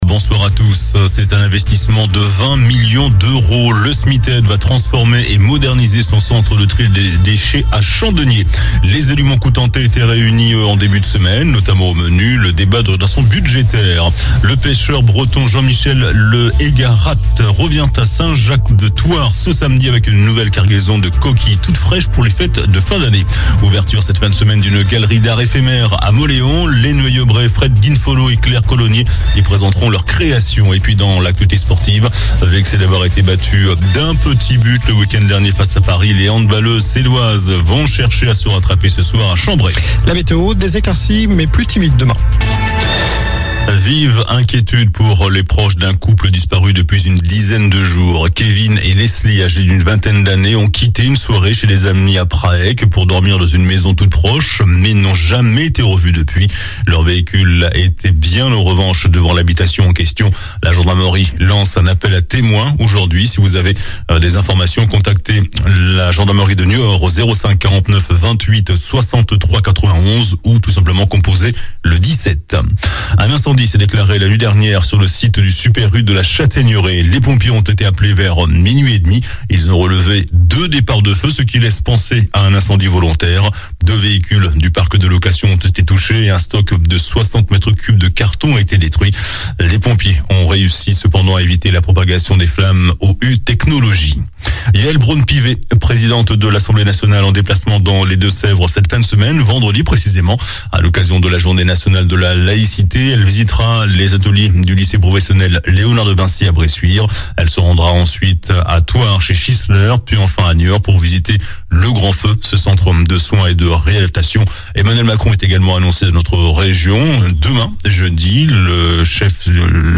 Journal du mercredi 7 décembre (soir)